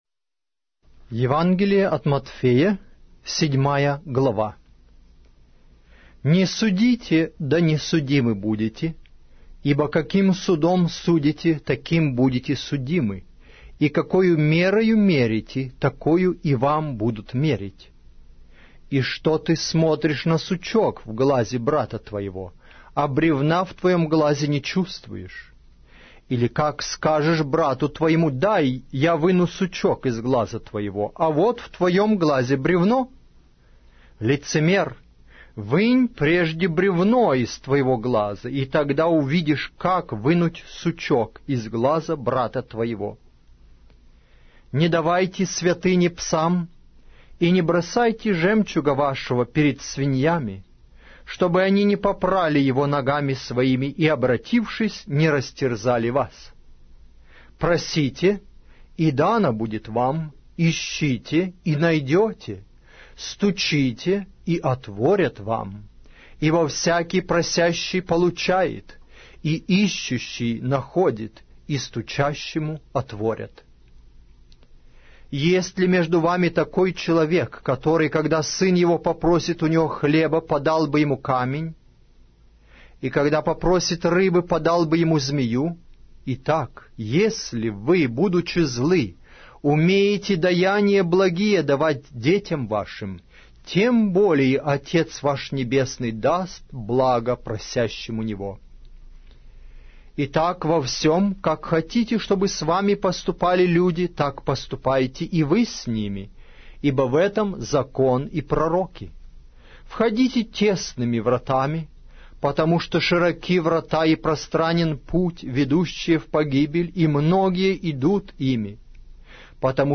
Аудиокнига: Евангелие от Матфея